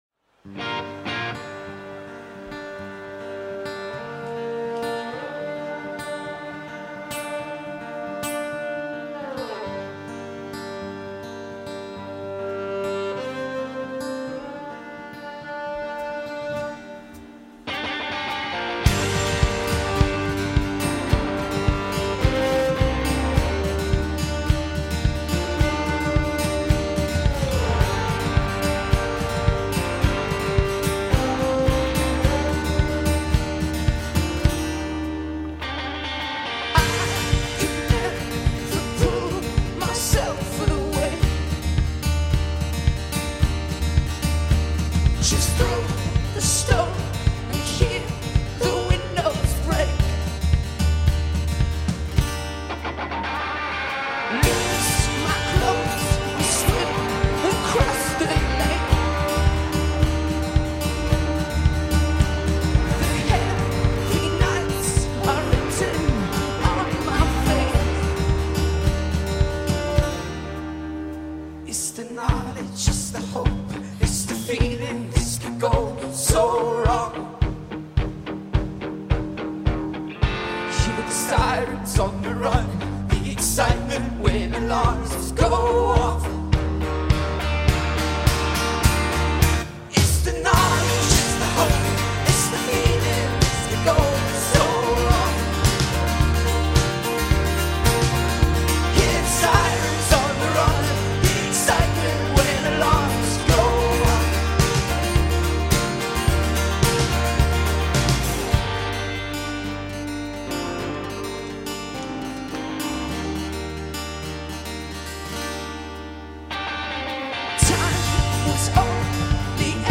Not that far removed in execution from Arcade Fire